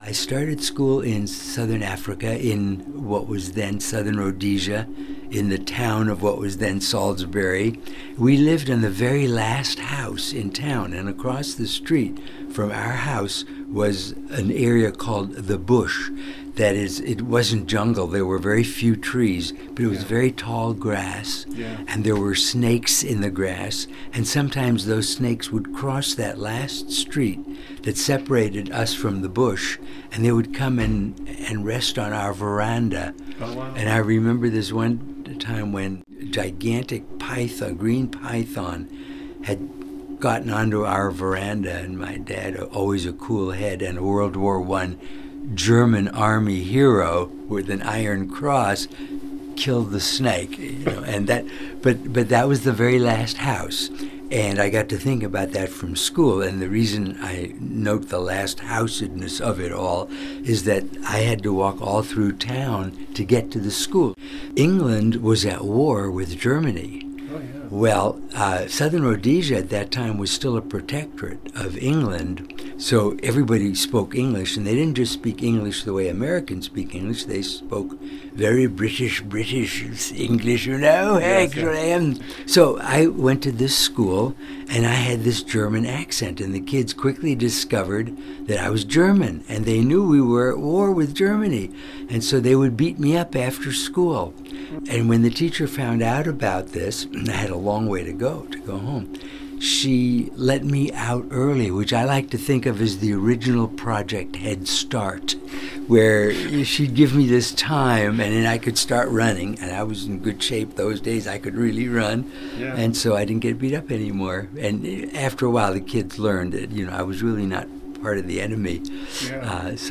I am pleased to present some tidbits from our conversation — all recalled by Dr. Rosenthal with his characteristic kindness and joviality.